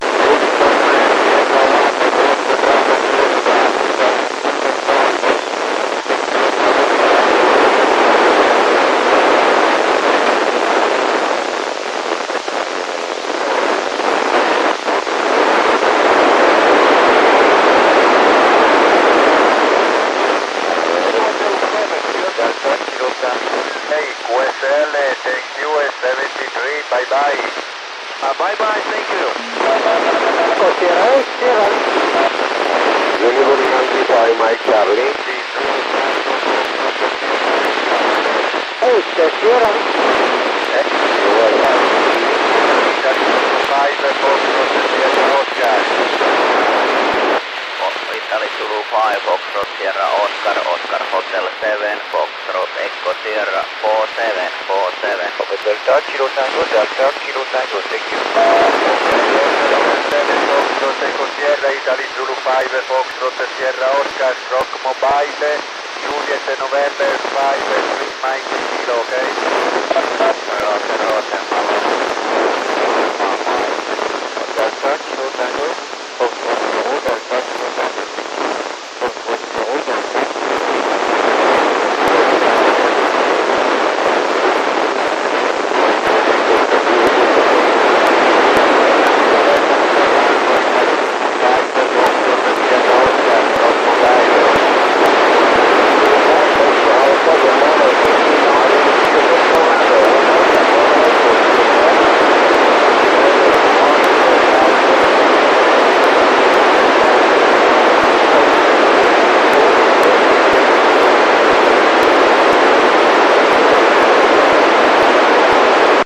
had an excellent voice contact lasting 7 minutes and using the S-band narrowband FM downlink. The downlink frequency was 2401.2 MHz +/- doppler.